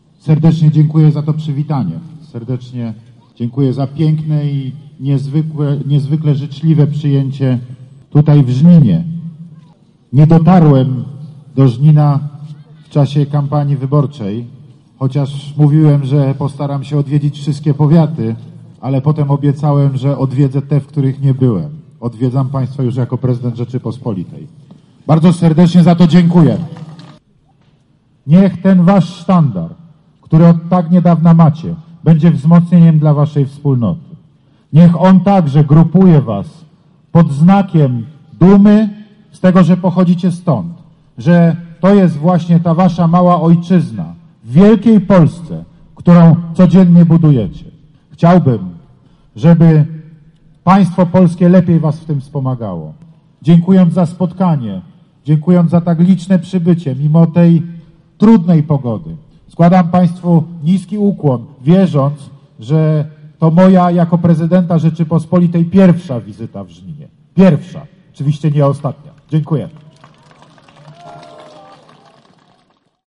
Wizytę rozpoczął od zwiedzenia średniowiecznej baszty, po czy czym spotkał się z mieszkańcami grodu Śniadeckich. Wygłosił też przemówienie, w którym wspomniał o 25-leciu samorządu terytorialnego obchodzonego w naszej gminie.